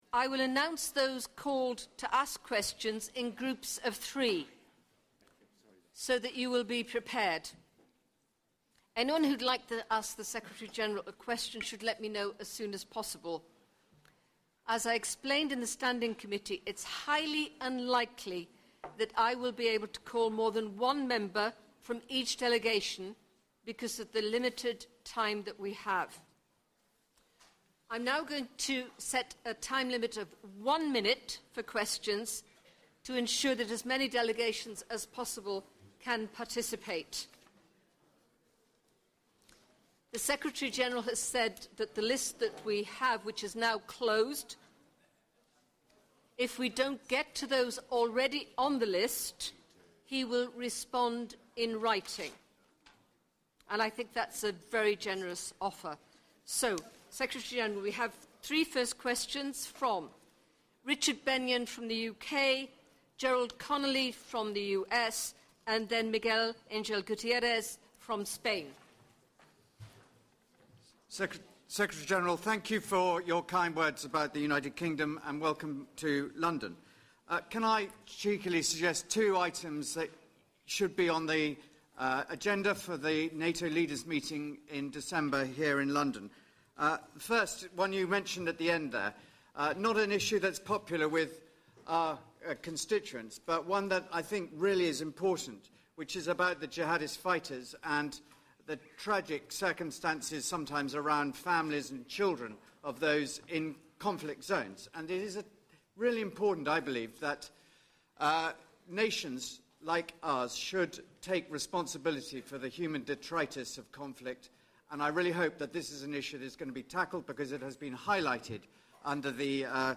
Speech
by NATO Secretary General Jens Stoltenberg at the NATO Parliamentary Assembly Plenary Session (London, Queen Elizabeth II Center)